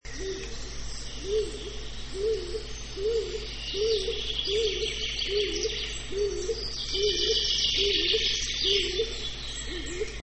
Siniak - Columba oenas